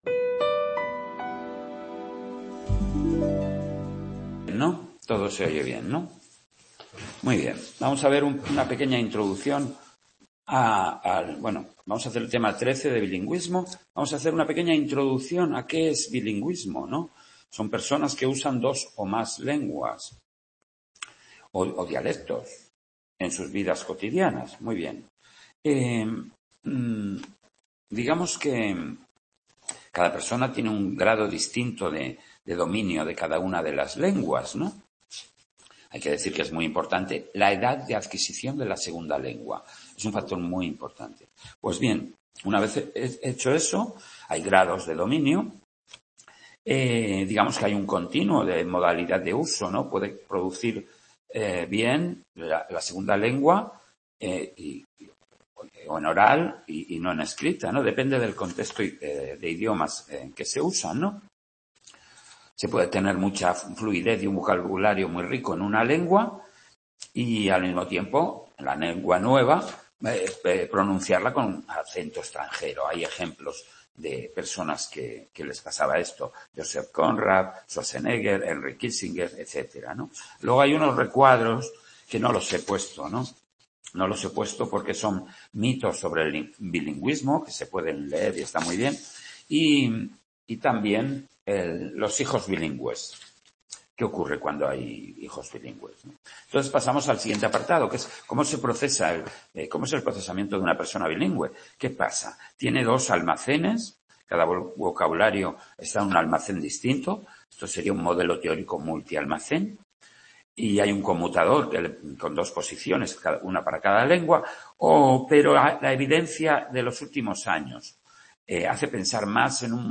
Tema 13 de Psicología del Lenguaje. "Bilingüismo". Grabación realizada en el Centro Asociado de Sant Boi de Llobregat